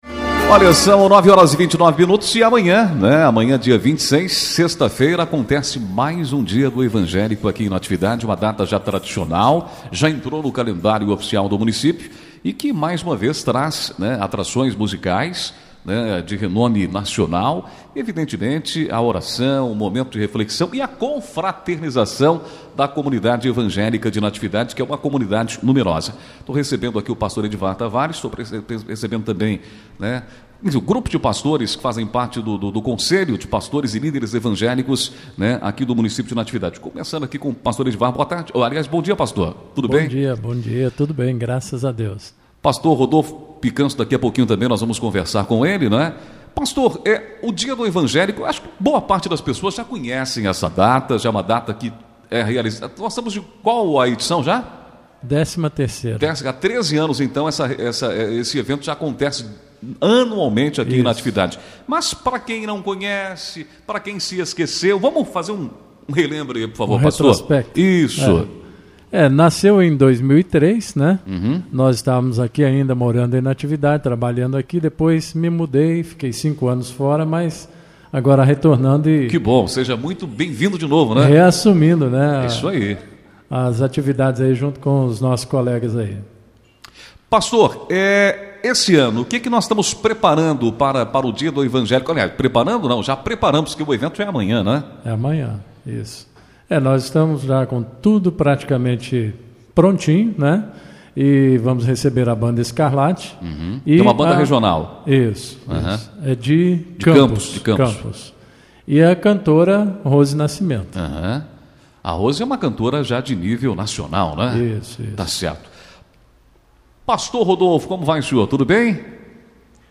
25 agosto, 2016 ENTREVISTAS, NATIVIDADE AGORA
ENTREVISTA-PASTORES.mp3